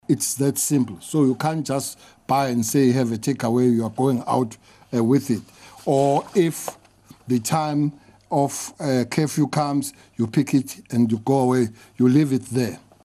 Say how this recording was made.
He briefed the media yesterday afternoon on law enforcement during lockdown level 1.